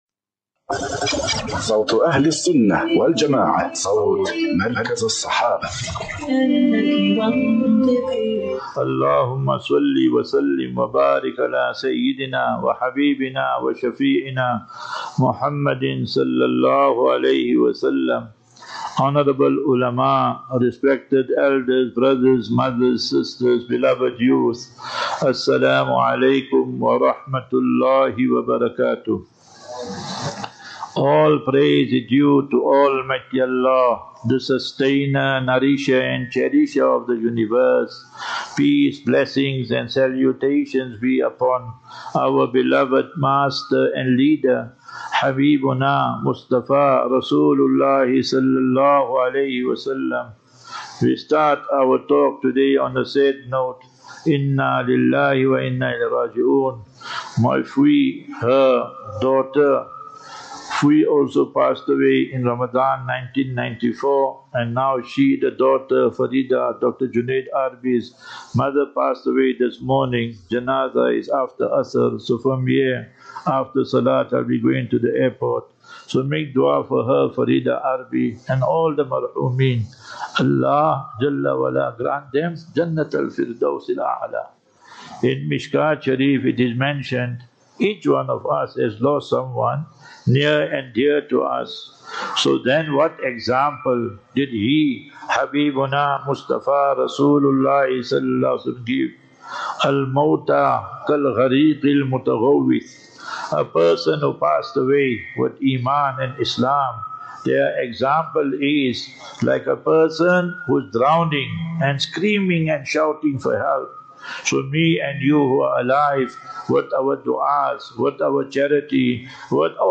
Jumu'ah Lecture at Masjid Abu Bakr Siddiq (RA) Mackenzie Park
Jumu'ah Lecture